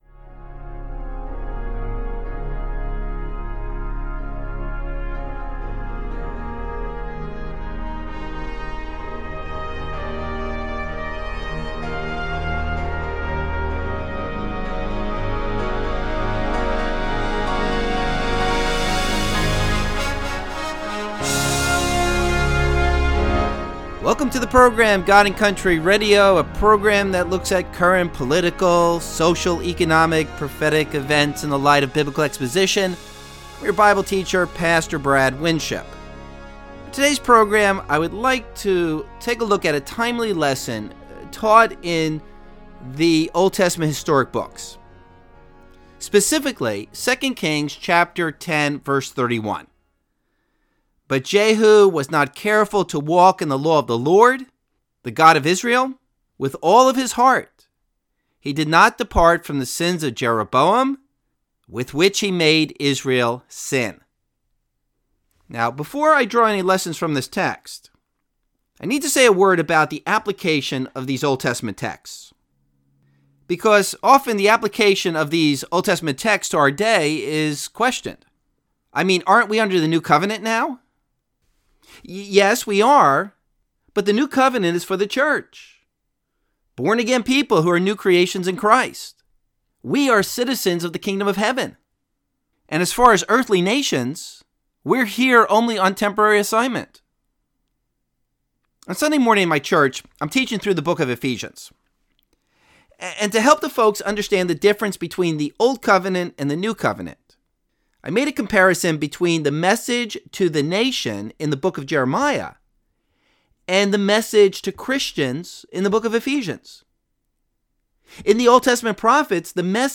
Abridged Radio Program